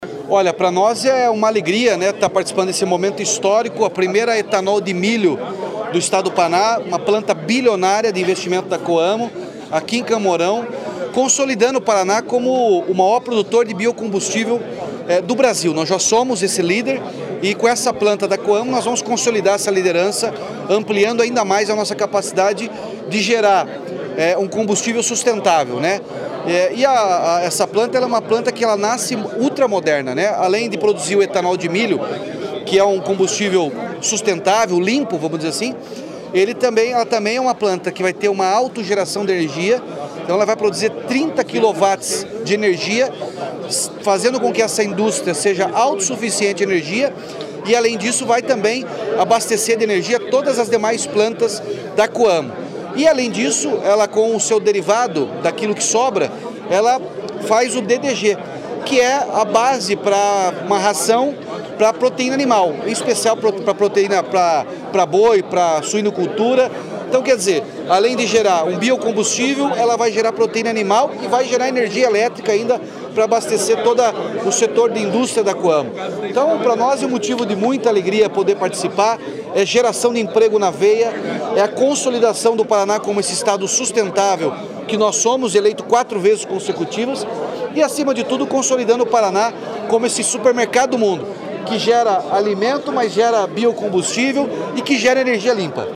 Sonora do governador Ratinho Junior sobre a entrega de licença para a fábrica de etanol de milho da Coamo